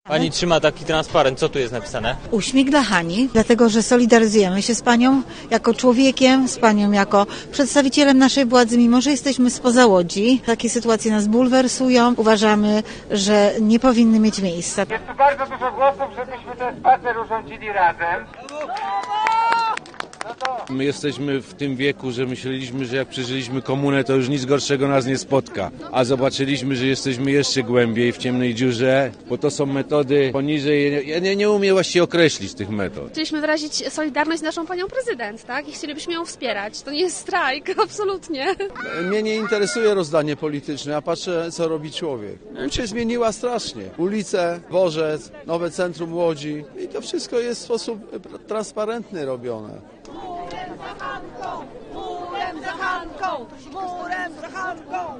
Nie mam nic do ukrycia” Maszerujący skandowali “Uśmiech dla Hanki”.